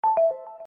Categoría Notificaciones